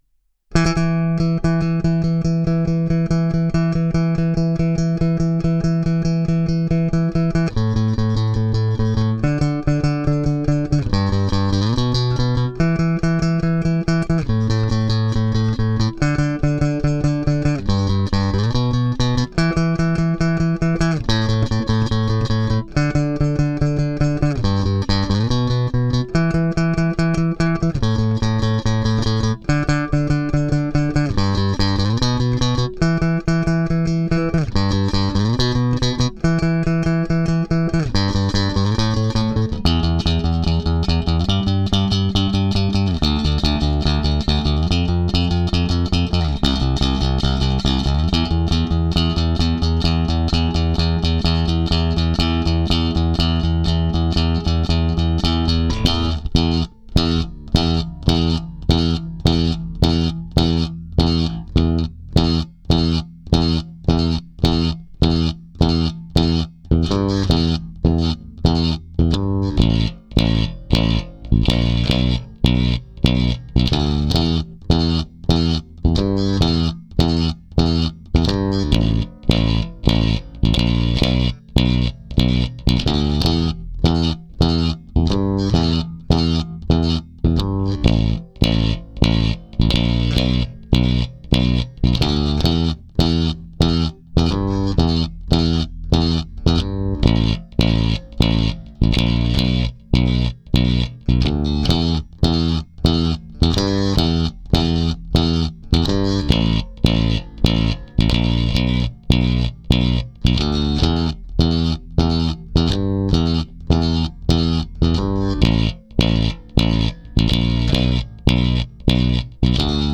bass only